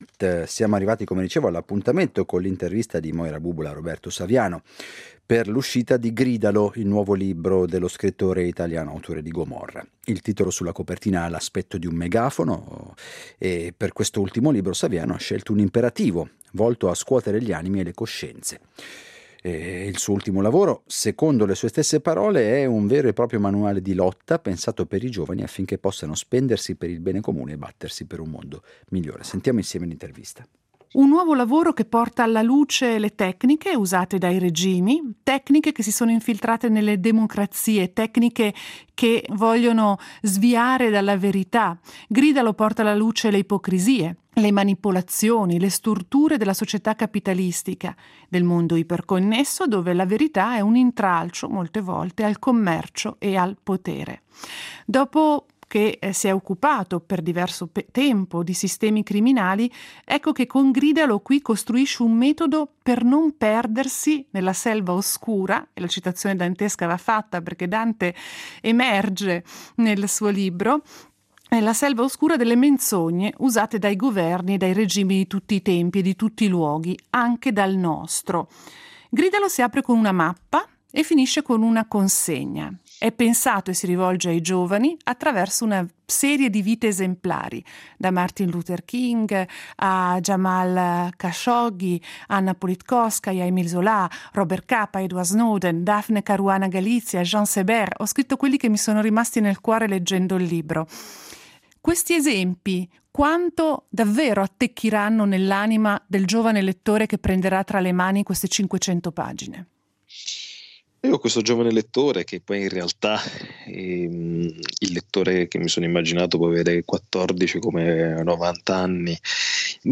lo ha intervistato Roberto Saviano